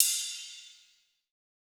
Roland.Juno.D _ Limited Edition _ Brush Kit _ Ride.wav